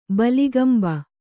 ♪ baligamba